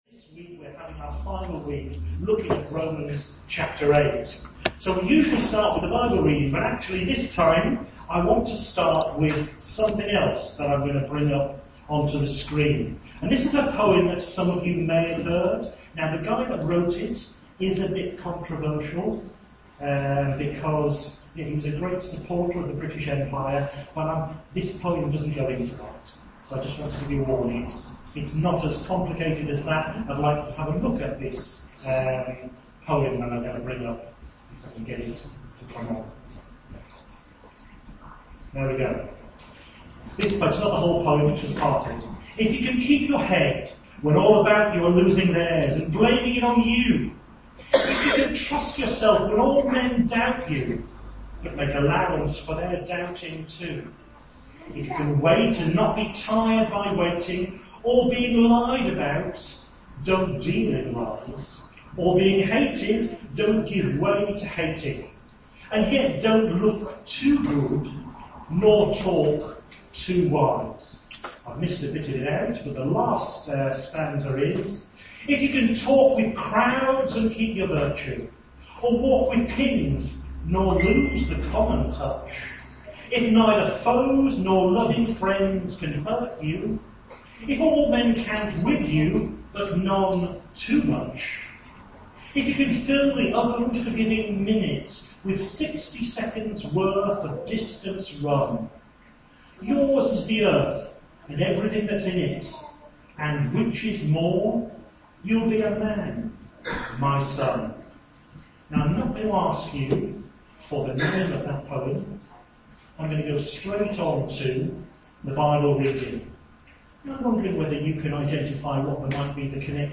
We apologise for the poor quality of this recording.